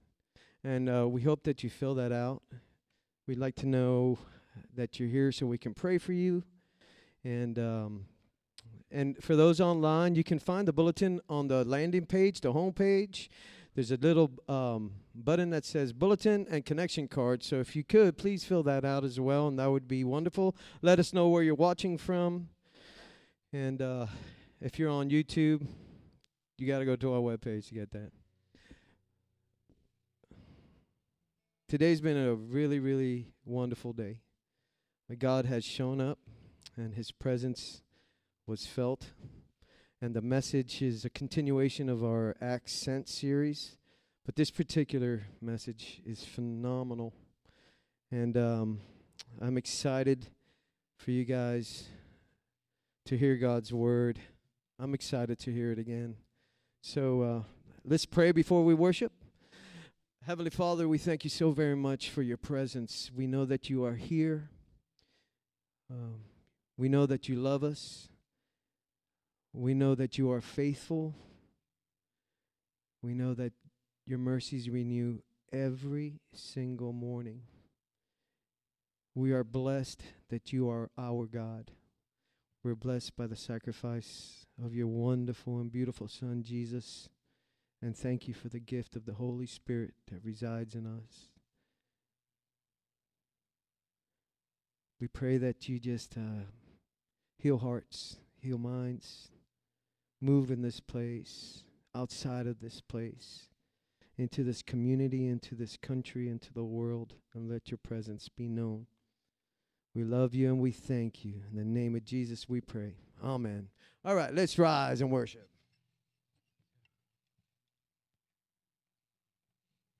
SERMON DESCRIPTION The movement was moving and things were looking good until this moment.